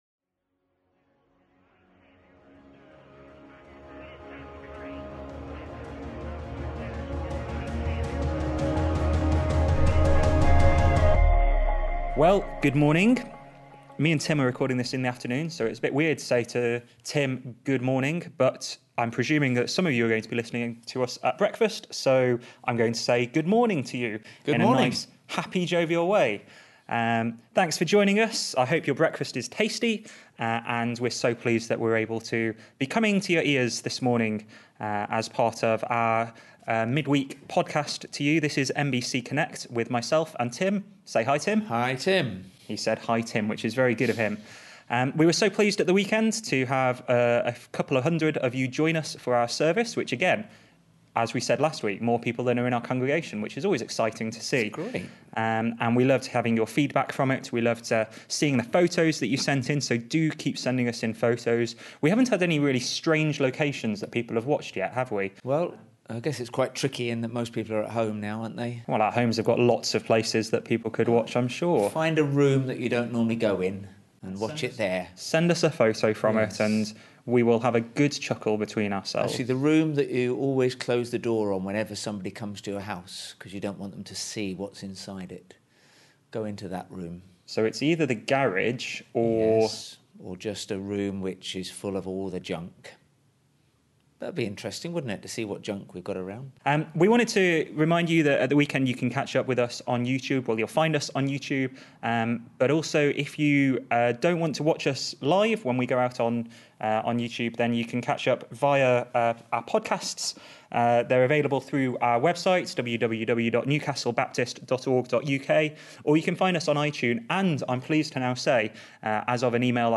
This week we chat about 'Standing in the Gap' and have a phone call with a friend of the church who is living in South Africa in the middle of a quarantined village. We are continuing to release a new podcast every Wednesday designed to help you Connect to God, Connect to One Another in our church family and Connect to Others in our communities.